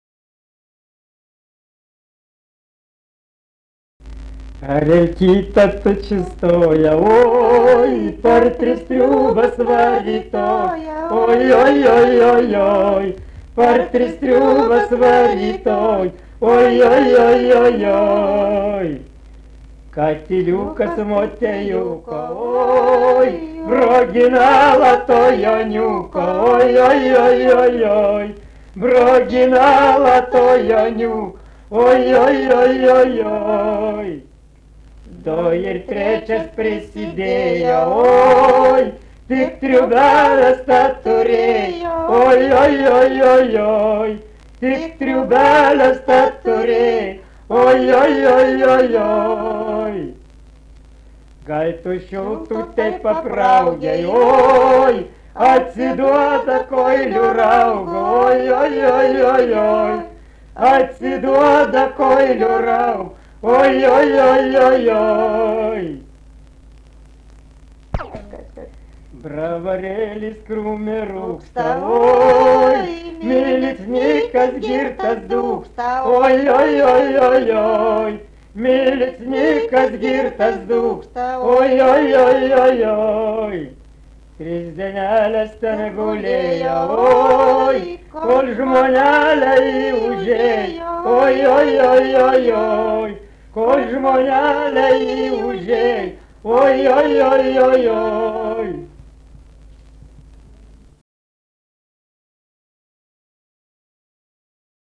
daina
Papiliai
vokalinis